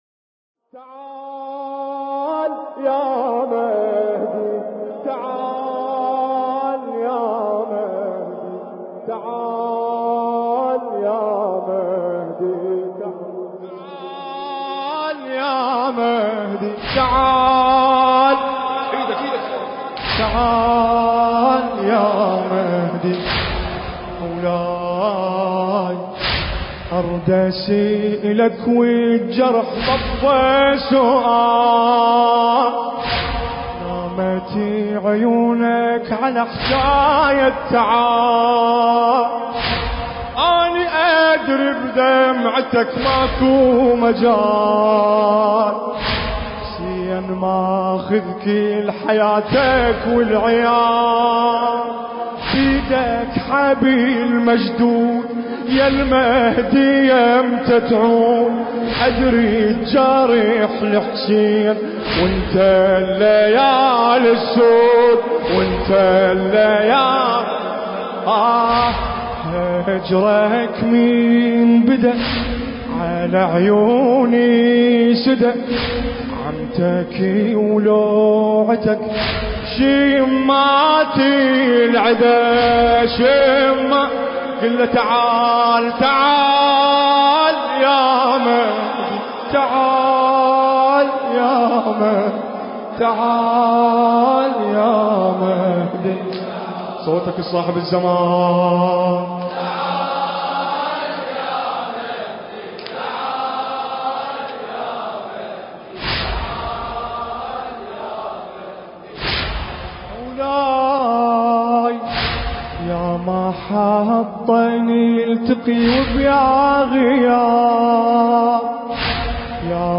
استقبال شهر محرم الحرام